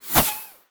bullet_flyby_02.wav